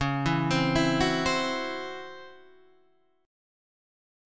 DbmM7bb5 Chord